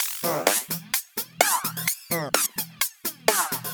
VEH1 Fx Loops 128 BPM
VEH1 FX Loop - 20.wav